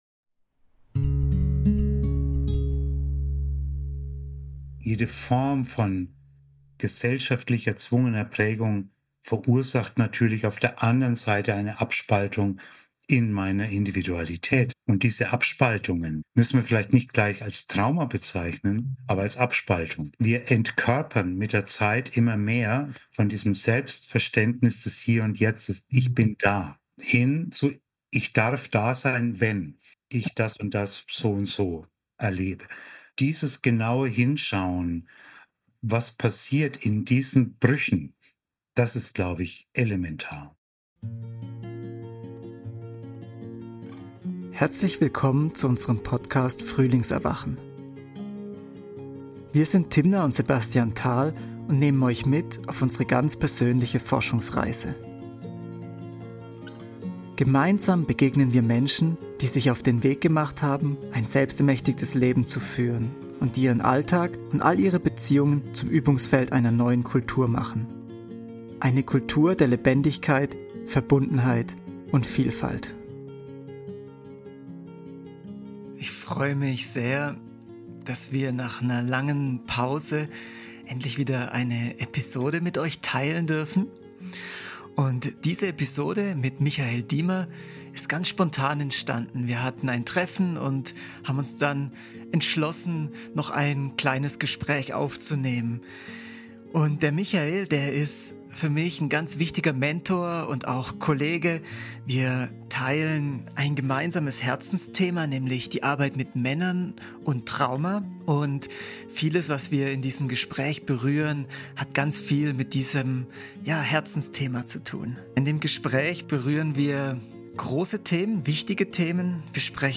Insgesamt ist es ein forschendes Gespräch, das bewusst keine fertigen Antworten formuliert, sondern dazu einlädt, in den Fragen zu verweilen und neugierig auf sich selbst zu werden.